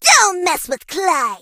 bonni_kill_vo_02.ogg